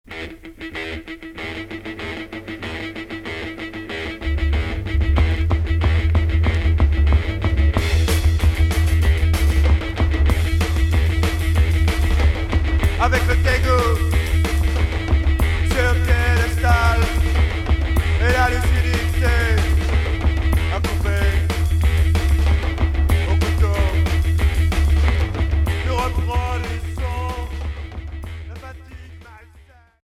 Punk rock Unique 45t retour à l'accueil